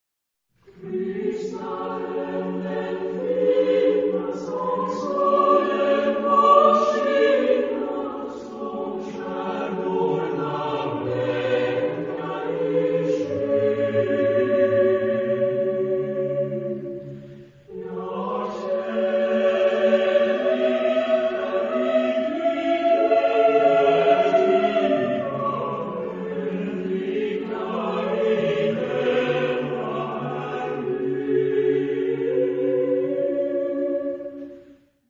Zeitepoche: 20. Jh.
Chorgattung: SATB  (4 gemischter Chor Stimmen )
von Eric Ericson Chamber Choir gesungen unter der Leitung von Eric Ericson